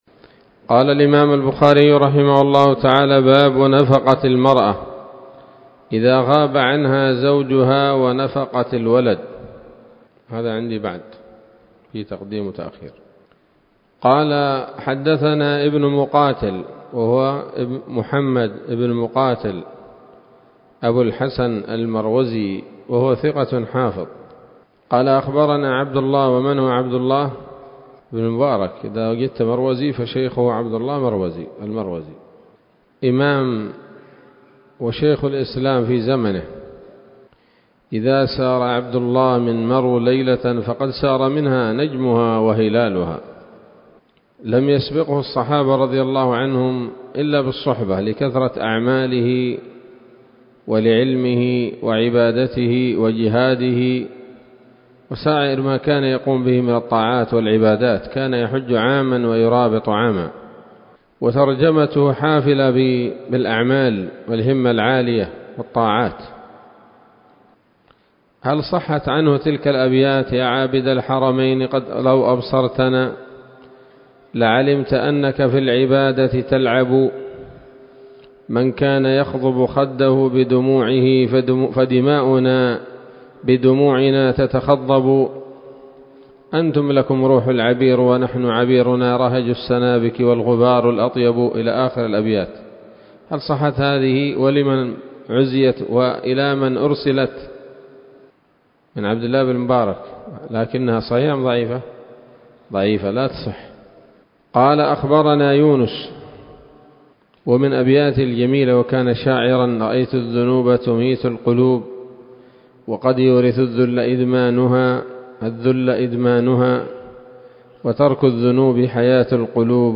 الدرس الرابع من كتاب النفقات من صحيح الإمام البخاري